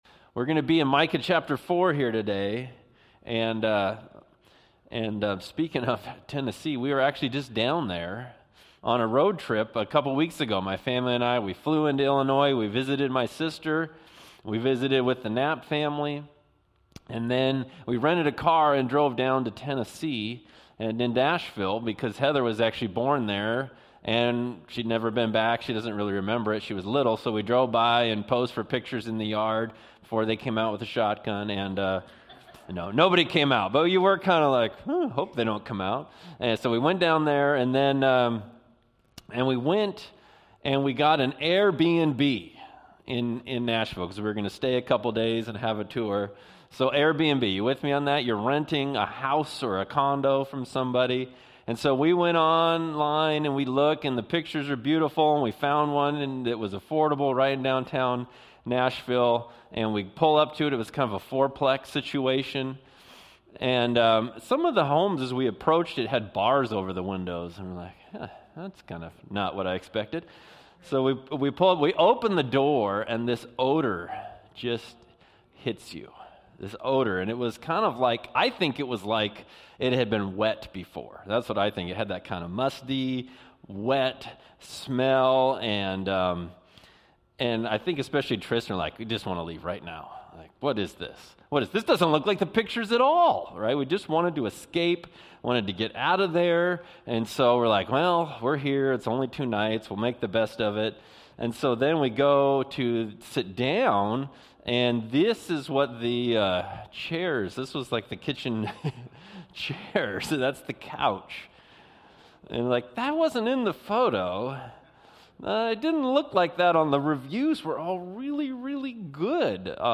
Sermons by Northwest Baptist Church (Bellingham, WA)